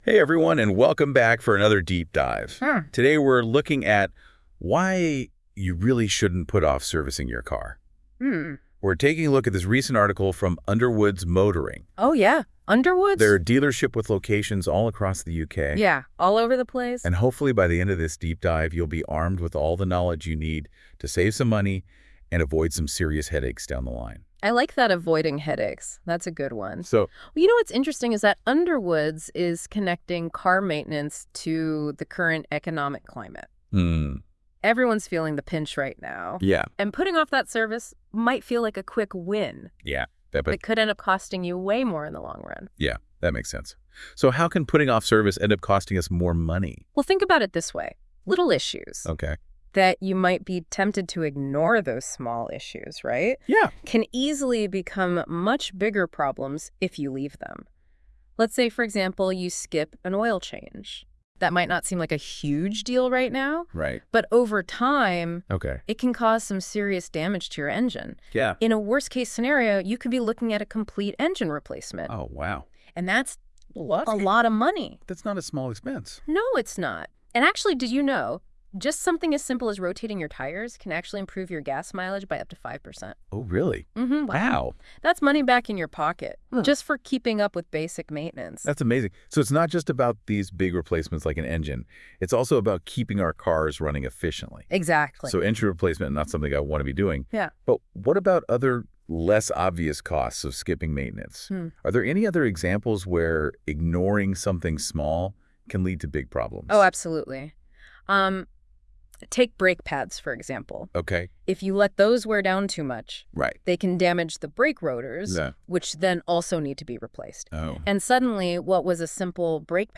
If you would prefer to hear this news blog generated as a conversational podcast by the astonishing powers of AI click here.